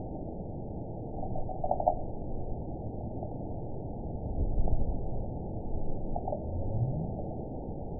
event 917870 date 04/19/23 time 09:09:10 GMT (2 years, 7 months ago) score 9.57 location TSS-AB03 detected by nrw target species NRW annotations +NRW Spectrogram: Frequency (kHz) vs. Time (s) audio not available .wav